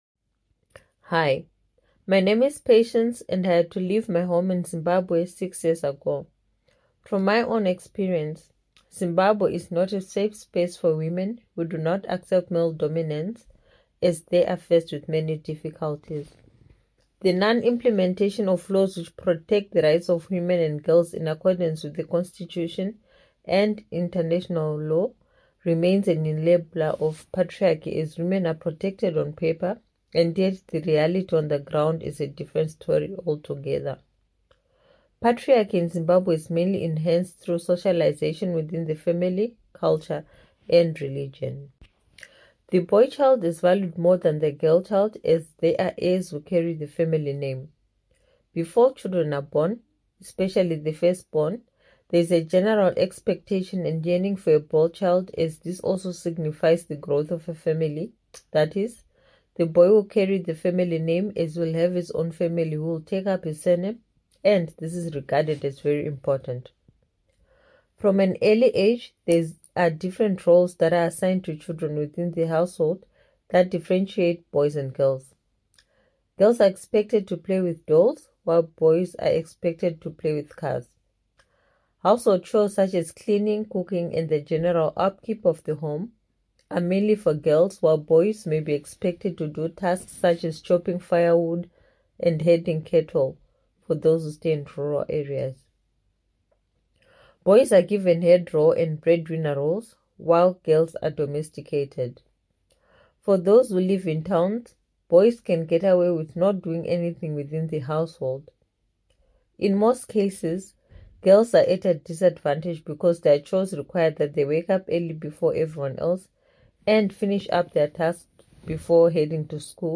The rally in Hamburg will air feminist music and short speeches from different parts of the world.